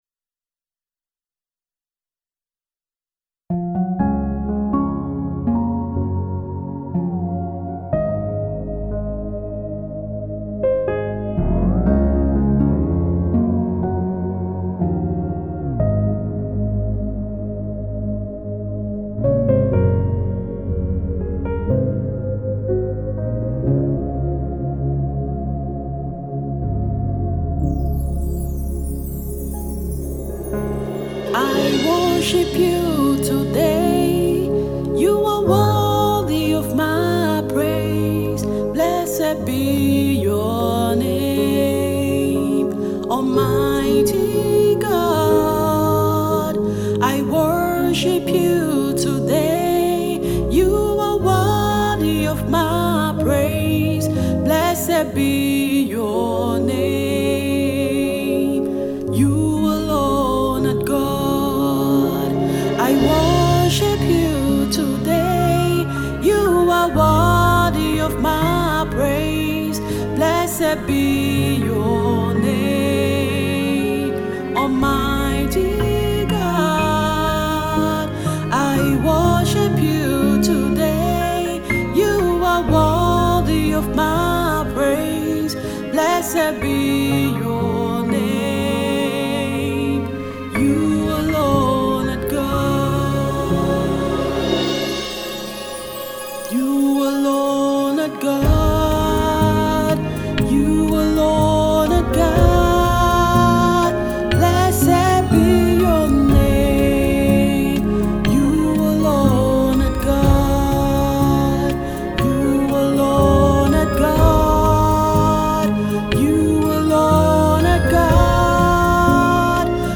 Gospel singer and Songwriter